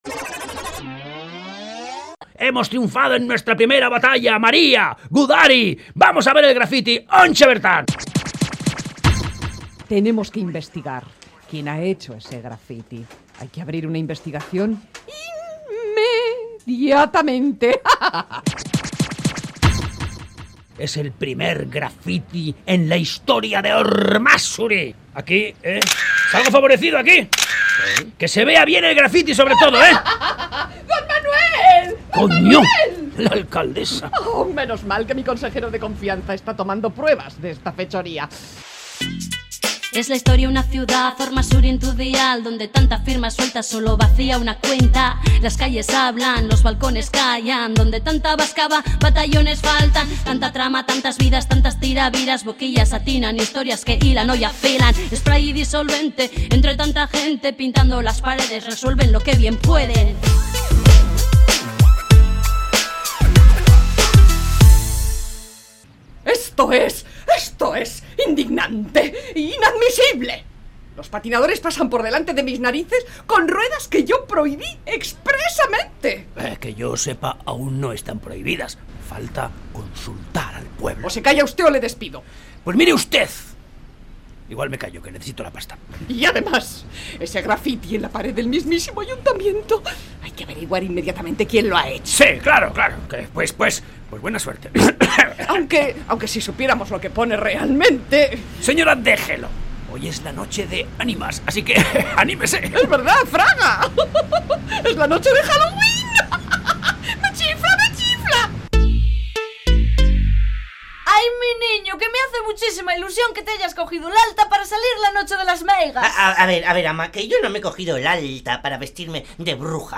Entrega número 19 de la Radio-Ficción “Spray & Disolvente”